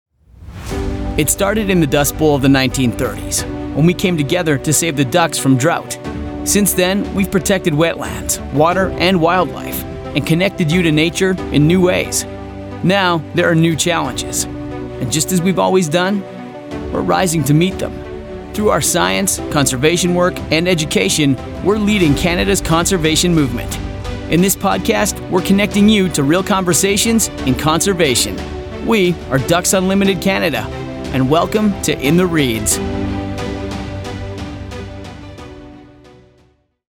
Anglais (canadien)
De la conversation
Réel
Croyable